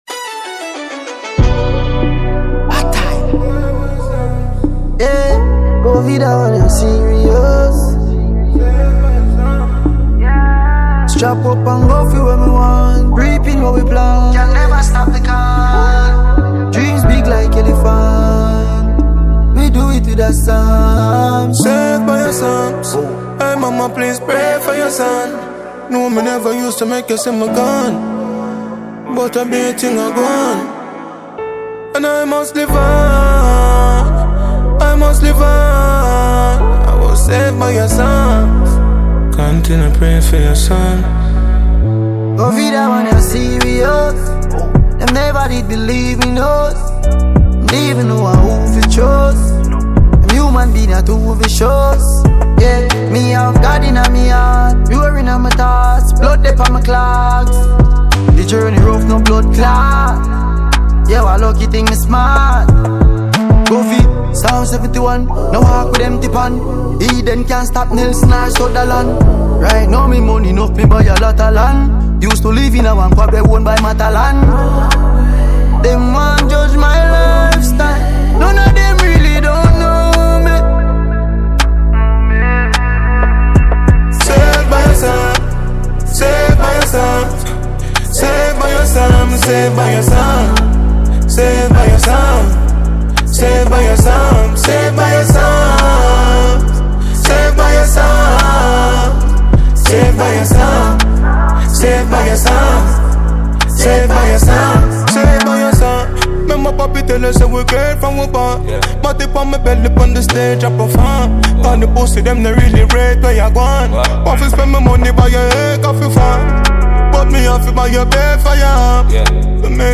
collaborative dancehall track